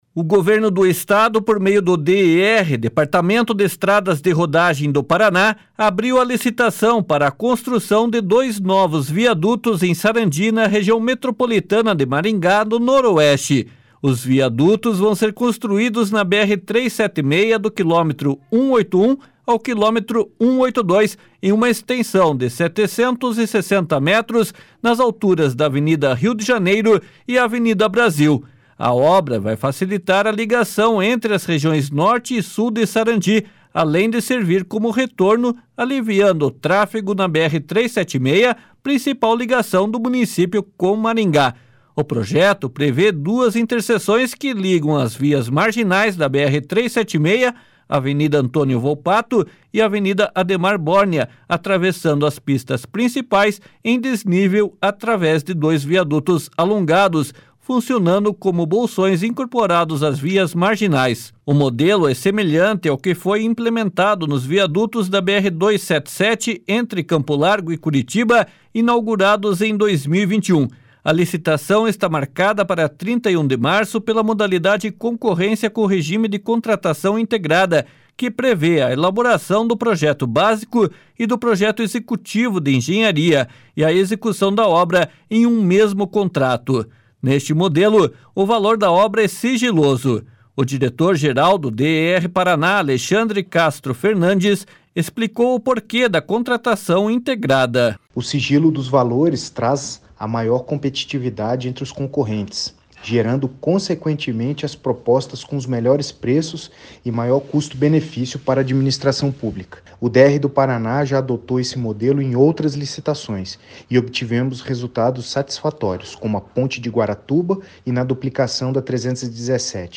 O diretor-geral do DER/PR, Alexandre Castro Fernandes, explicou o porquê da Contratação Integrada.